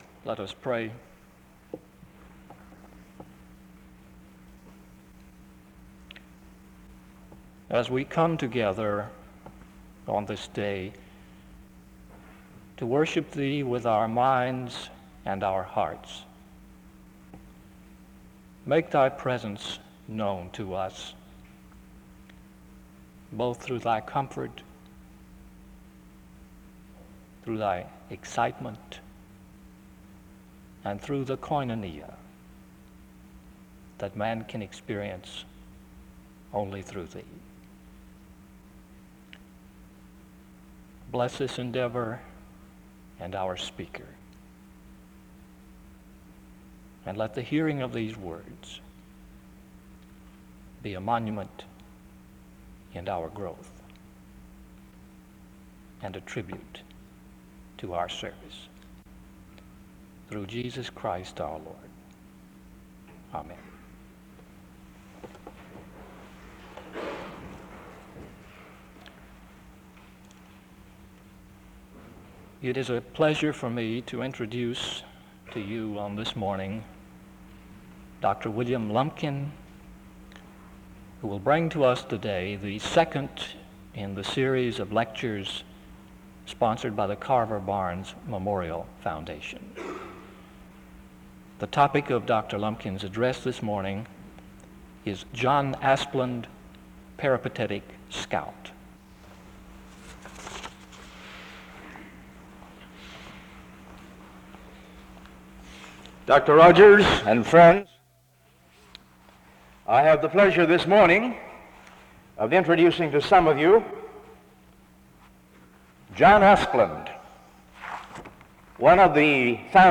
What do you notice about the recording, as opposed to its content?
The service begins with a prayer (0:00-1:07).